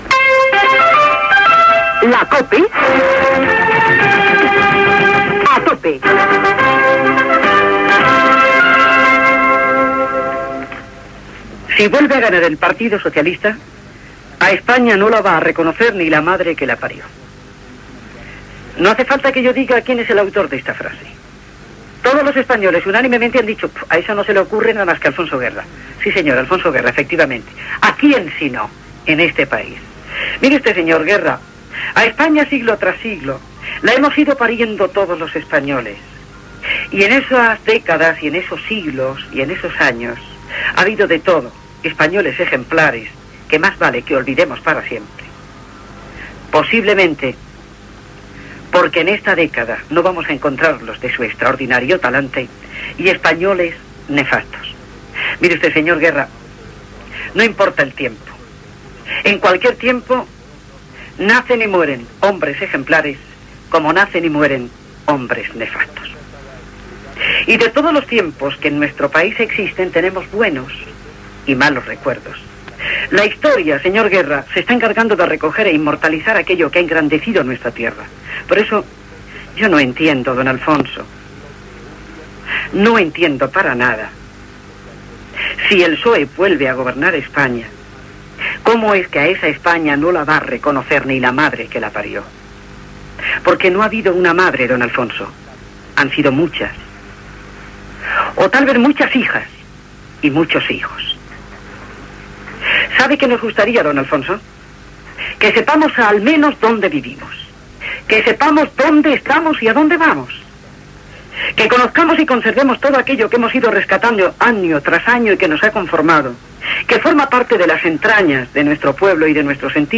Indicatiu de la cadena, portada del programa sobre el PSOE i el seu dirigent Alfonso Guerra. Crèdits del programa.
Entreteniment